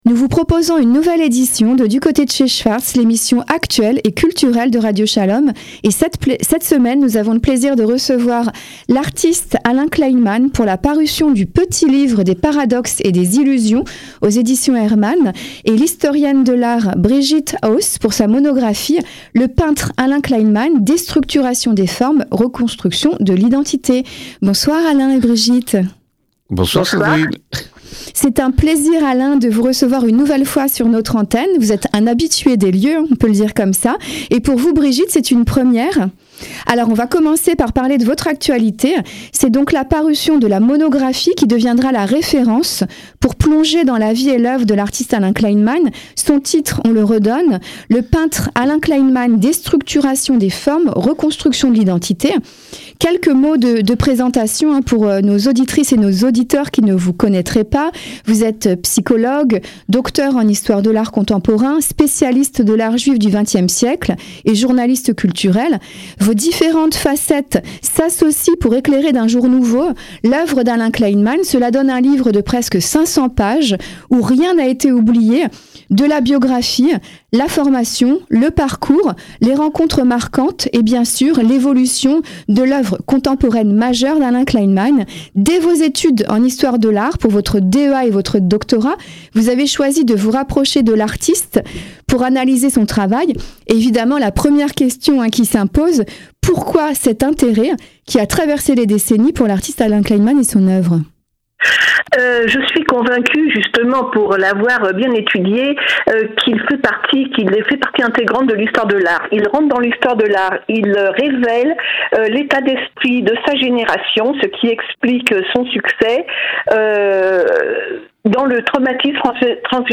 Entretiens radiophoniques